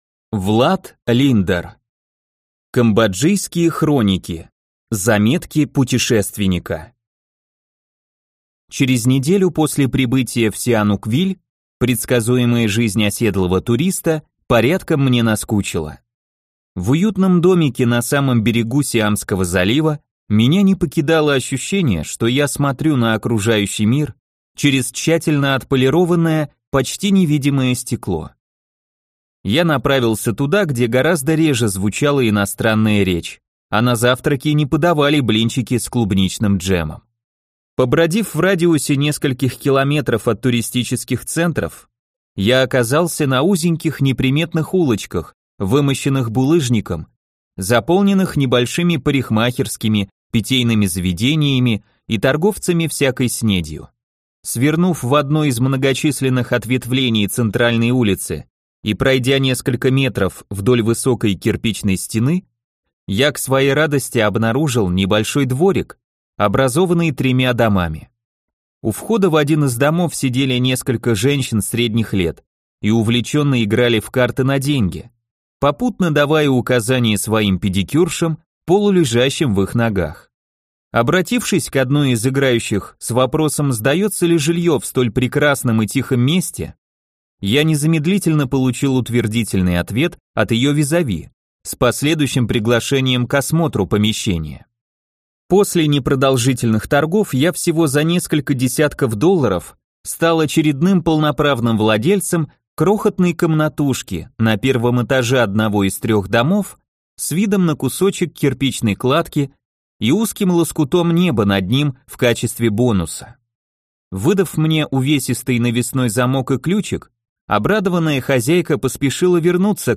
Аудиокнига Камбоджийские Хроники. Заметки путешественника | Библиотека аудиокниг